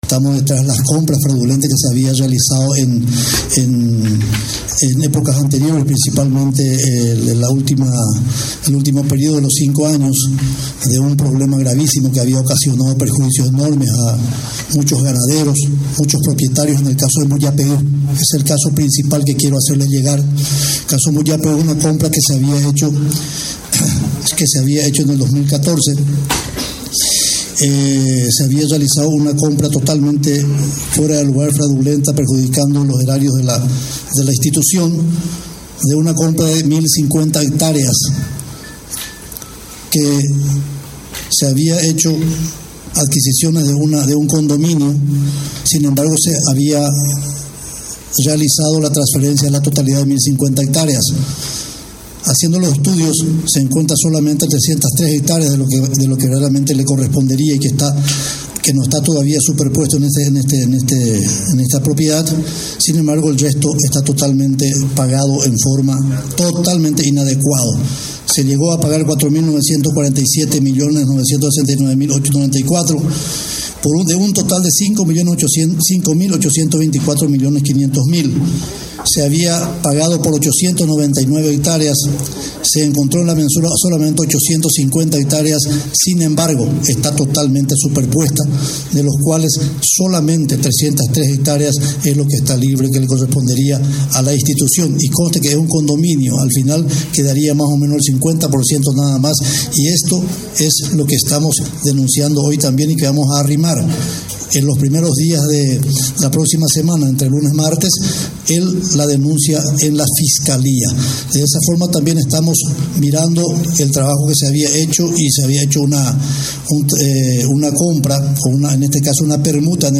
Torres llamó a una conferencia hoy para dar su versión en torno al presunto esquema de coimas dentro de la institución, el cual está siendo investigado por el Ministerio Público.